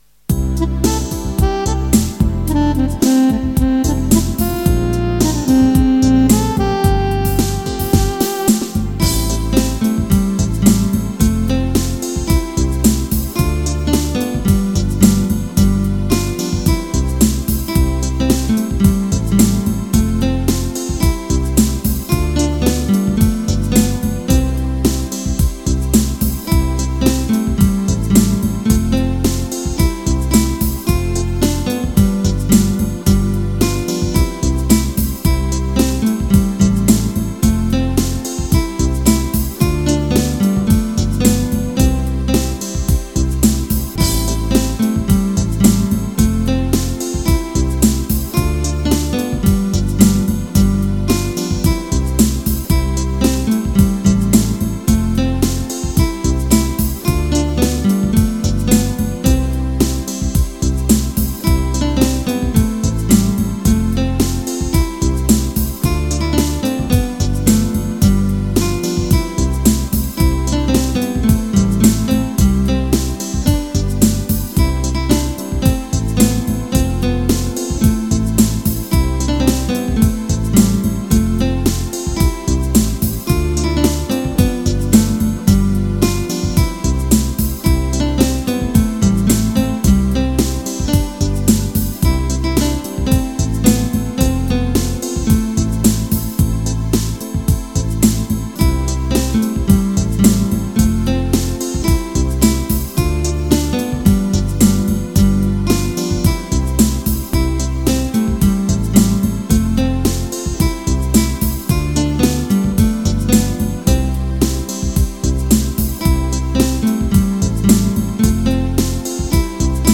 Keyboard-Version